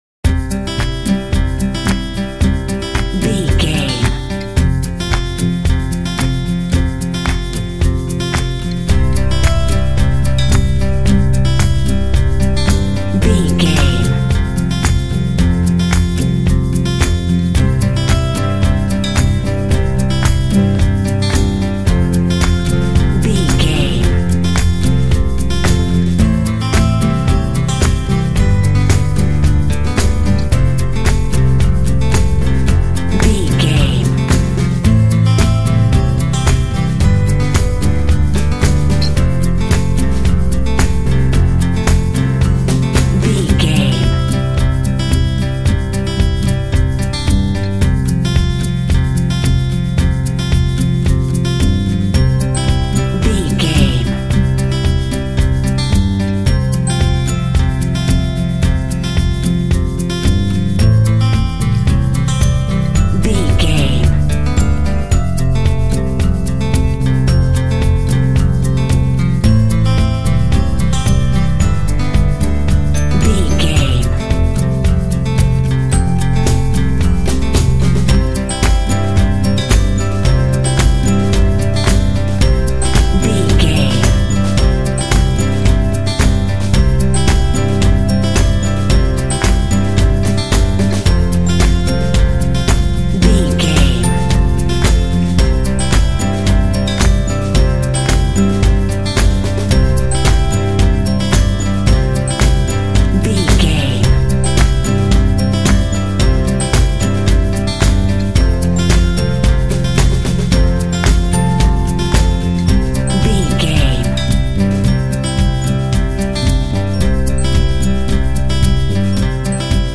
Uplifting
Ionian/Major
cheerful/happy
joyful
acoustic guitar
bass guitar
drums
percussion
electric piano
indie
pop
contemporary underscore